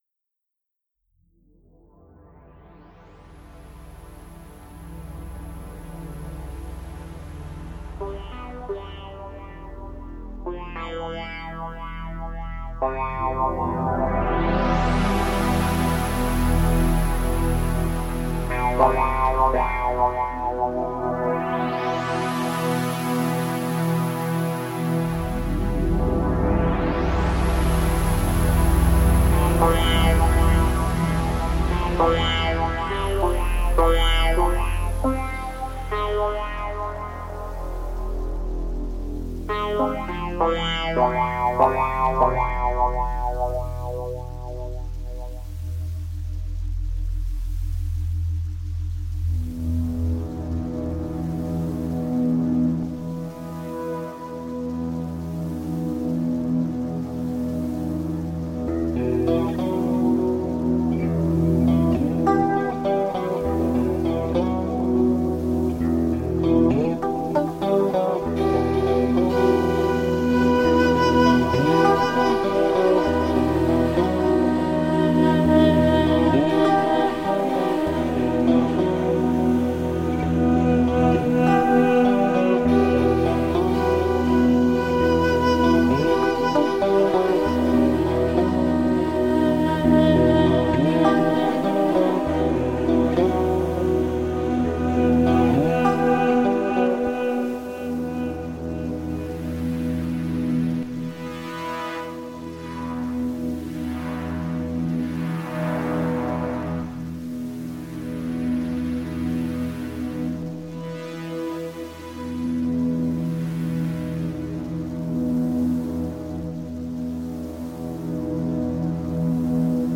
Mindfulness audio binaural.
mi-musica-relajante.mp3